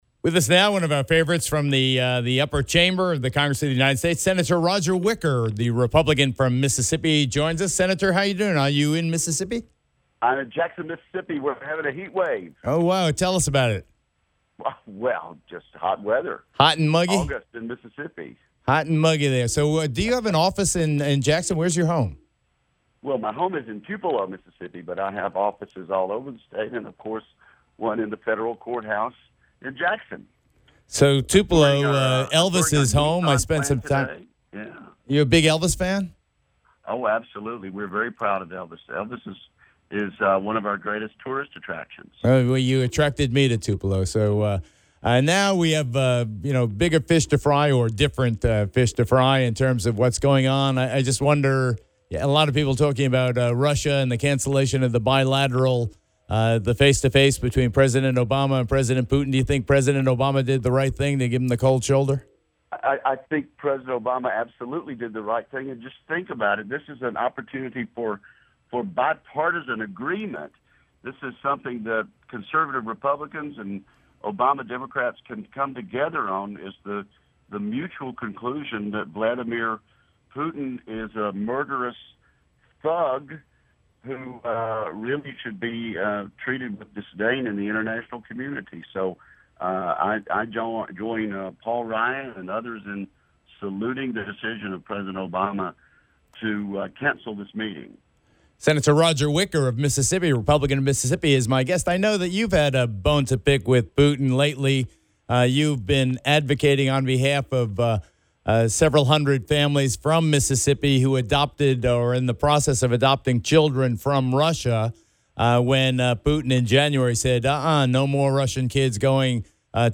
Wicker Interview with Geraldo Rivera